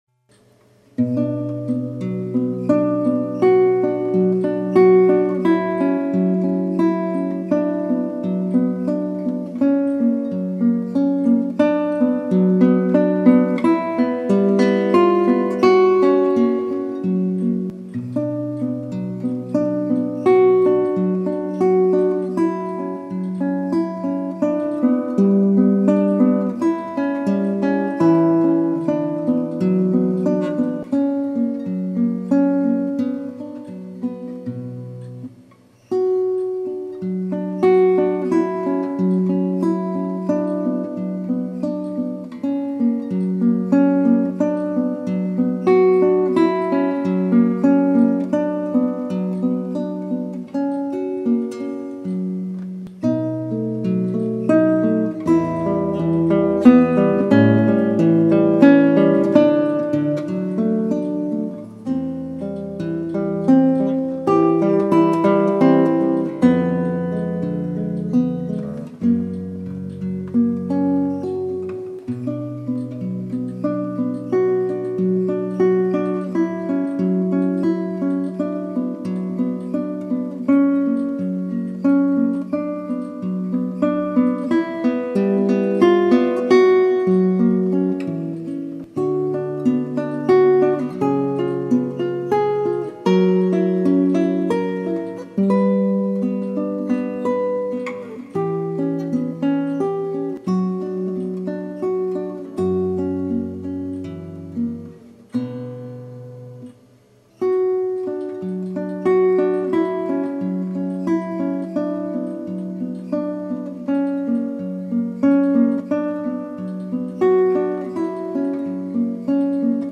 Đặc biệt có một tuyển tập gồm 20 bài, trong đó bài tập số 5 cung Si thứ rất được ưa chuộng.